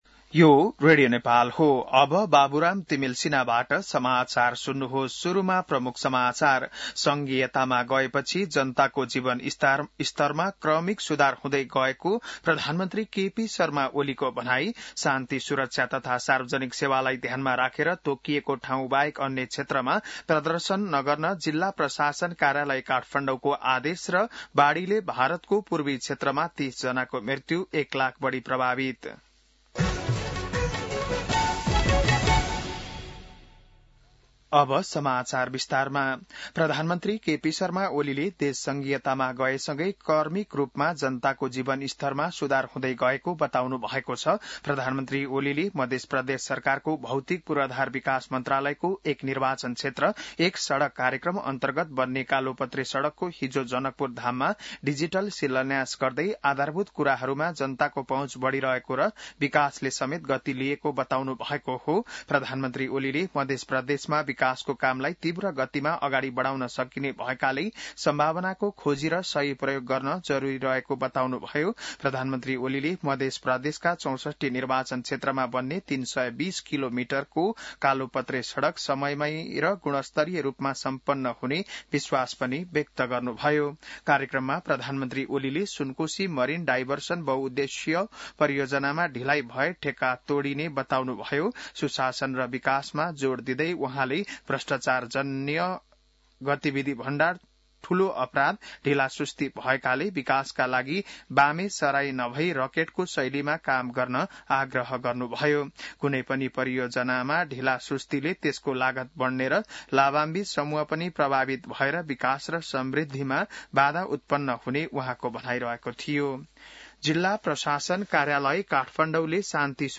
बिहान ९ बजेको नेपाली समाचार : १९ जेठ , २०८२